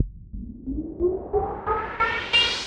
Index of /musicradar/rhythmic-inspiration-samples/90bpm
RI_ArpegiFex_90-04.wav